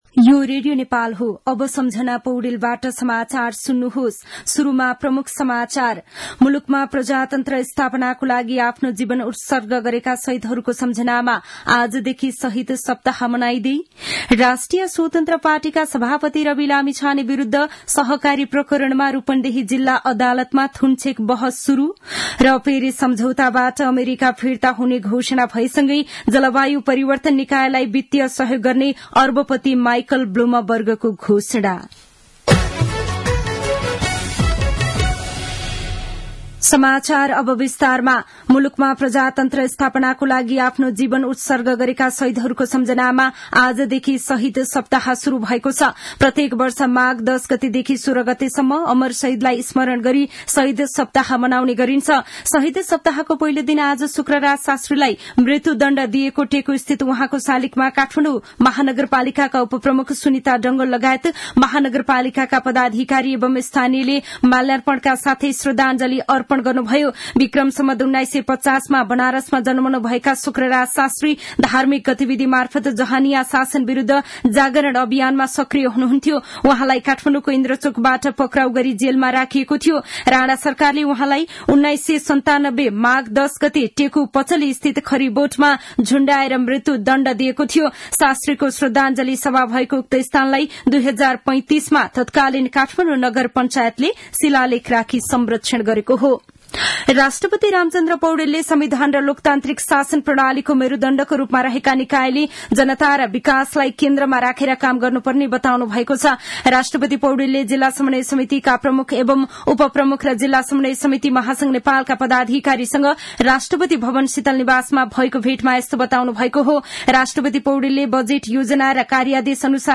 दिउँसो ३ बजेको नेपाली समाचार : ११ माघ , २०८१
3-pm-news-2.mp3